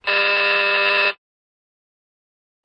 Door Buzzer, High Pitched.